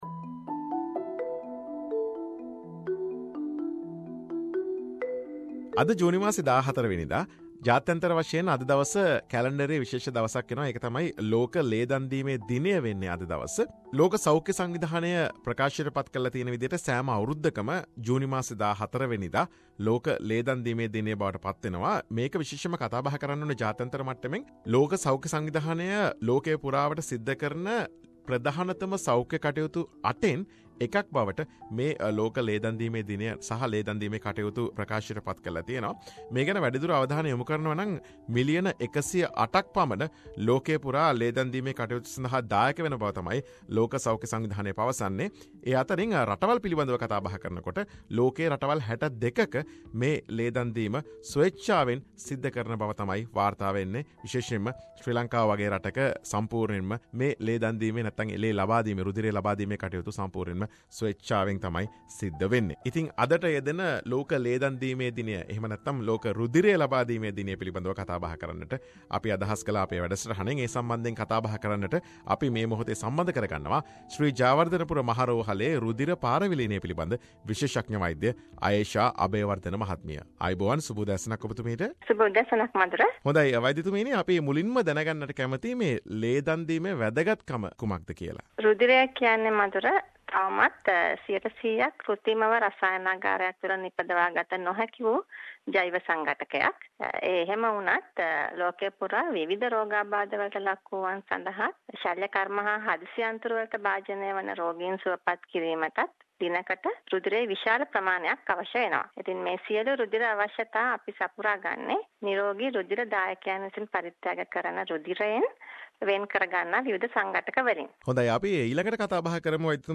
Discussion about the importance of blood donation and it's process